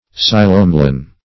Psilomelane \Psi*lom"e*lane\, n. [Gr. ? bare + ?, ?, black.]